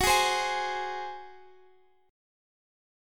Listen to F#sus2b5 strummed